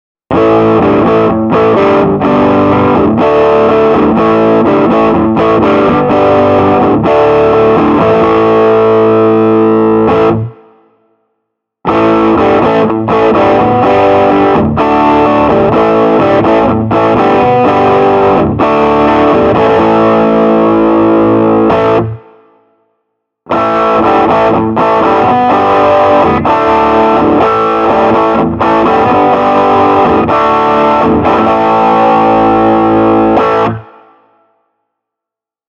Vox Escort & Epi Casino & Boss SD-1 – overdrive
Epiphone Casino –> Boss SD-1 –> Vox Escort (Normal) –> 2 x Samson C02 –> Focusrite Saffire 6 USB –> iMac/Garageband ’11
Reverb was added at mixdown.
vox-escort-epi-casino-boss-sd-1.mp3